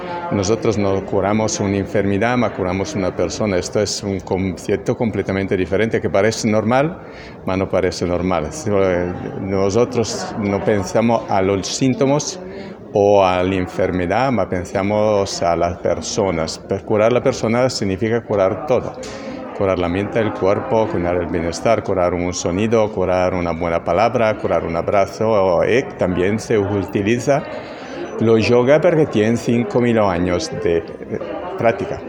entrevista.wav